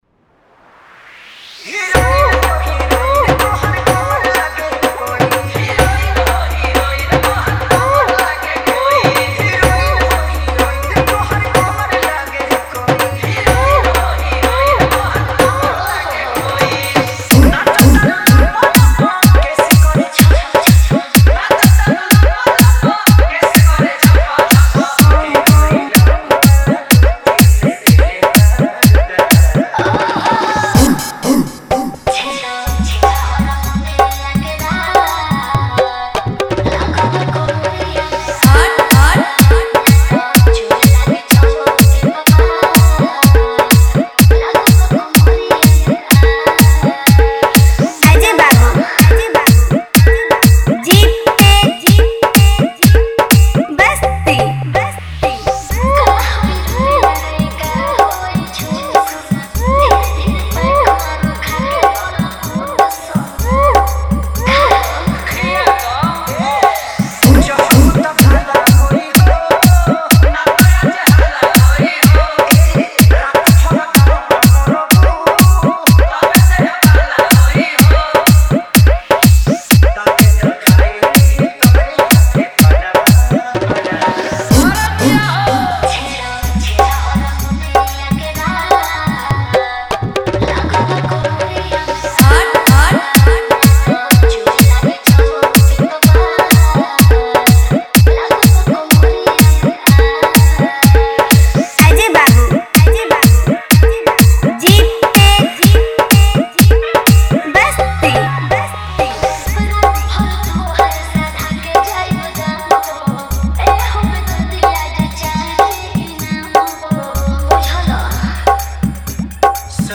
Category : Bhojpuri Wala Dj Remix